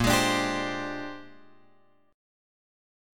A# 13th